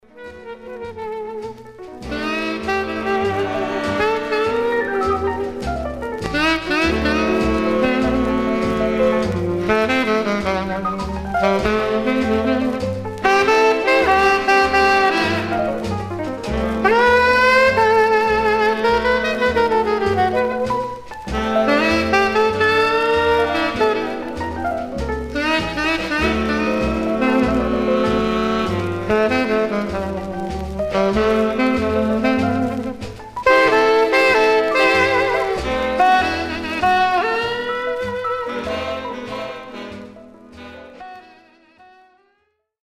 Stereo/mono Mono
R&B Instrumental Condition